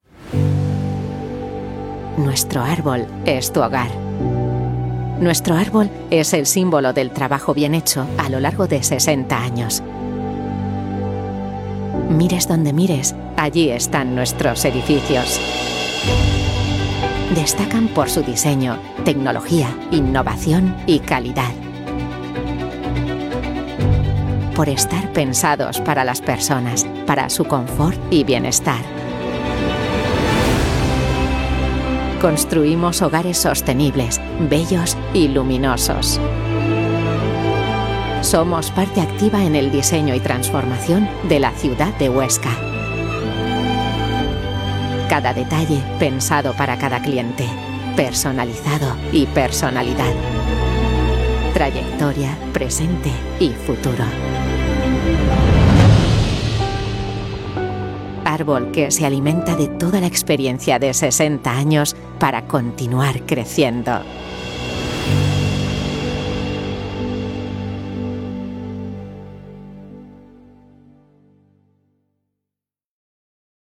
Comercial, Cálida, Natural, Versátil, Empresarial
Corporativo